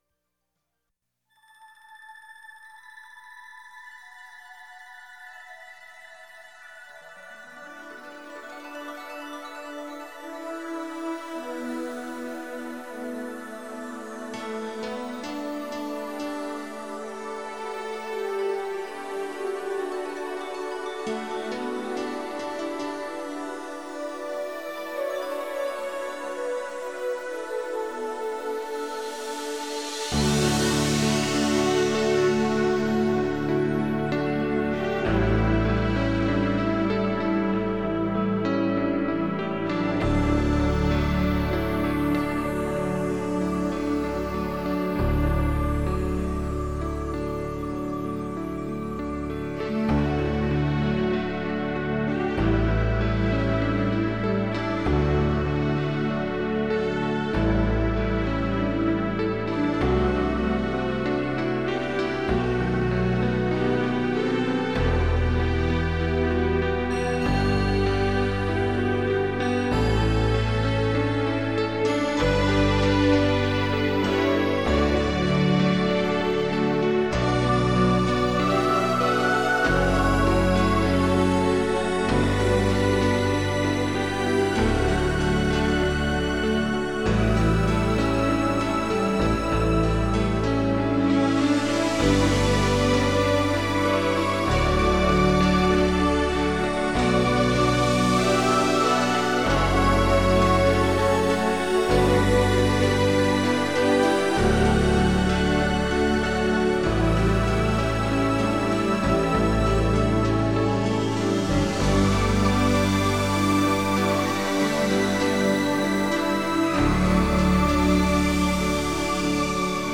آهنگ بی کلام secret vowes